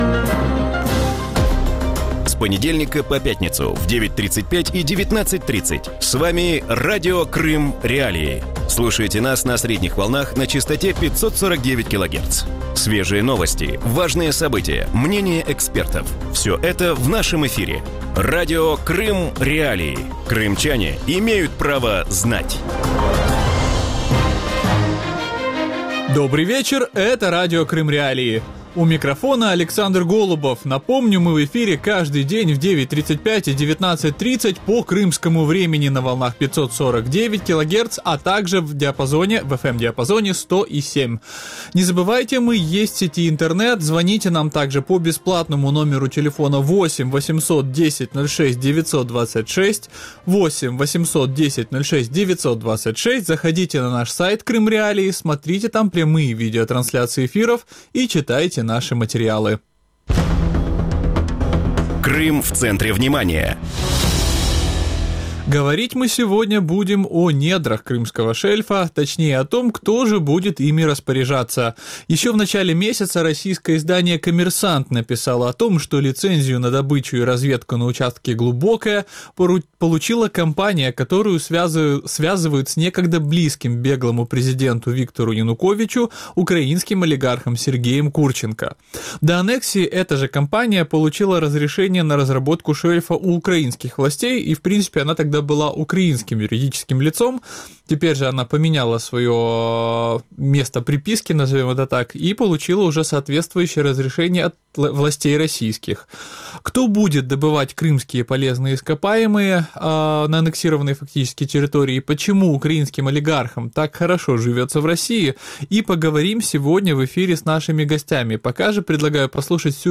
В вечернем эфире Радио Крым.Реалии обсуждают получение лицензии на разработку газового месторождения в Черном море компанией, которую связывают с Сергеем Курченко, одним из соратников Виктора Януковича. Какие российские компании продолжают разрабатывать черноморский шельф после аннексии Крыма?